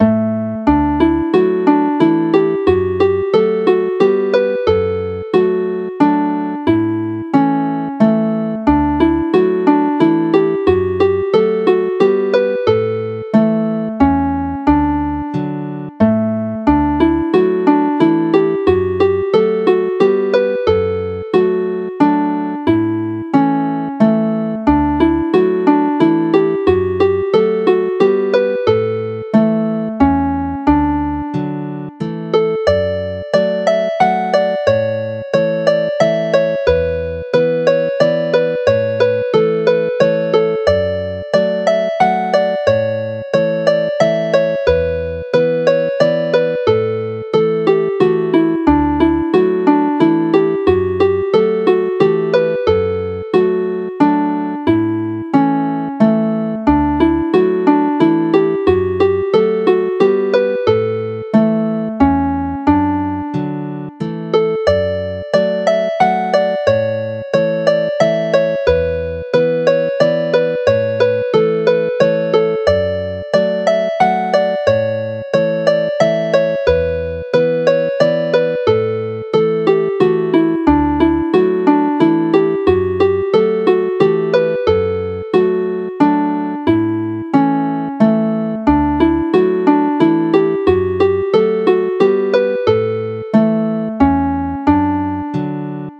Chwarae'r alaw'n araf
Play the melody slowly